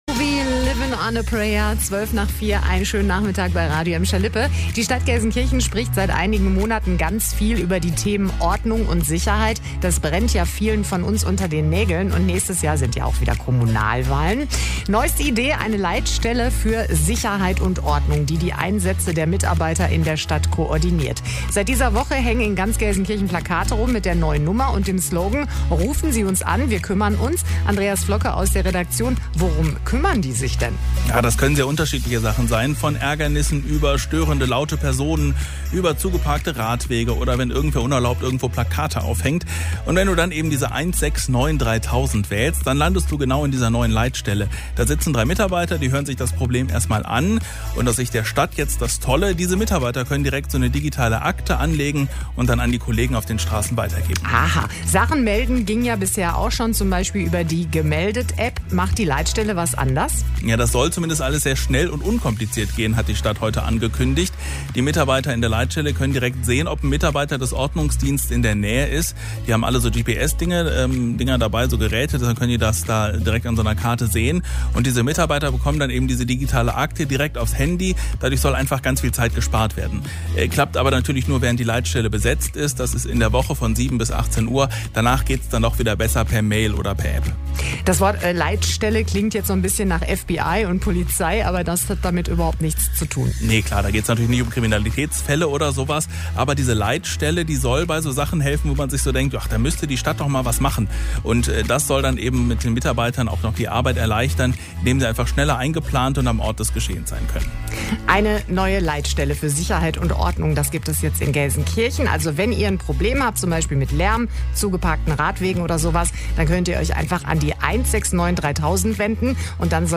mitschnitt-ordnungsamt-hotline.mp3